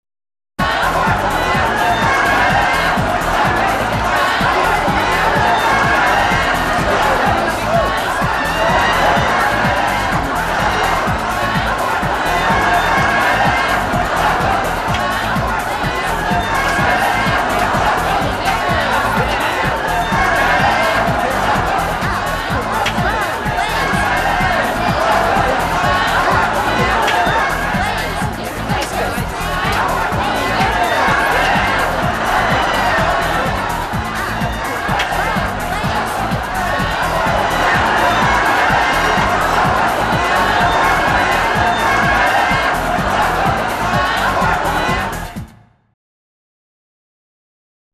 FIESTA PARTY
Ambient sound effects
Descargar EFECTO DE SONIDO DE AMBIENTE FIESTA PARTY - Tono móvil
Fiesta_Party.mp3